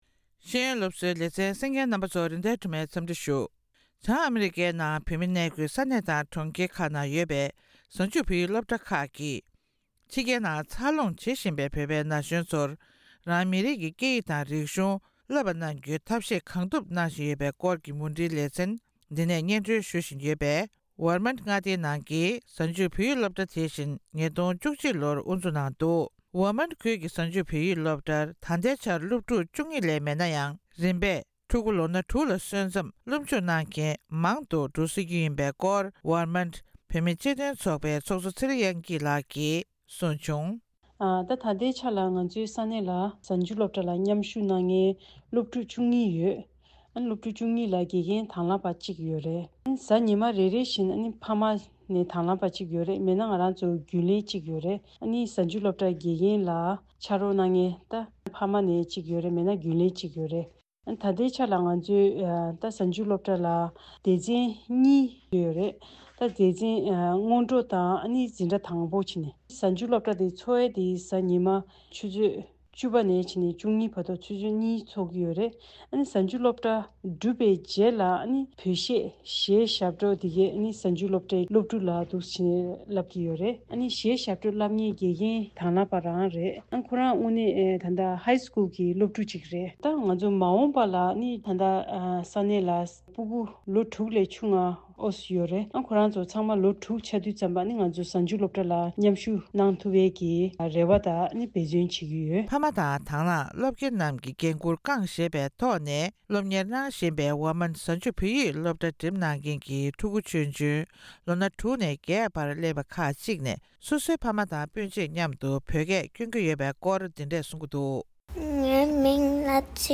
ཨ་མི་རི་ཀའི་་ཝར་མོནྚ་མངའ་སྡེའི་བོད་མིའི་སྤྱི་མཐུན་ཚོགས་པའི་ཁྱབ་ཁོངས་གཟའ་མཇུག་བོད་ཡིག་སློབ་གྲྭ་འགྲིམས་མཁན་བོད་ཕྲུག་དང་ཕ་མ་ཁག་ཅིག་གིས་ཕྱི་རྒྱལ་ཡུལ་གྲུའི་ནང་ཕྲུ་གུར་རང་མི་རིགས་ཀྱི་སྐད་ཡིག་སློབ་ཁྲིད་ཐད་ཕ་མ་རང་གིས་ཤུགས་སྐྱོན་པའི་ཐོག་འབད་བརྩོན་ཡང་ཆེན་པོ་གནང་དགོས་ཀྱི་ཡོད་པའི་སྐོར་གསུང་པ